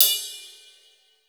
Closed Hats
pcp_ride02.wav